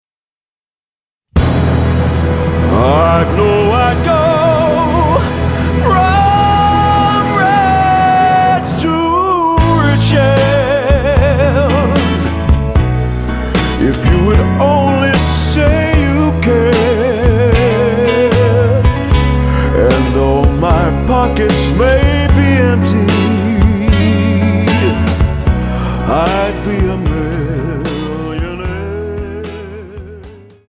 --> MP3 Demo abspielen...
Tonart:E Multifile (kein Sofortdownload.
Die besten Playbacks Instrumentals und Karaoke Versionen .